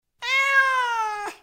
meow.wav